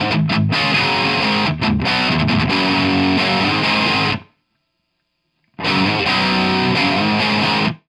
For all examples the guitar used is an SG with a Burstbucker 2 pickup in bridge position.
All mics were placed directly in front of the speakers roughly focused between the center cone and the outer edge at a 45deg angle.
No settings were changed on the amp or guitar during the recordings and no processing or eq was applied to the tracks.
Sennheiser MD421 street price $380
Dynamic-MD421
Dynamic-MD421.wav